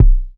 CDK - CMP Kick.wav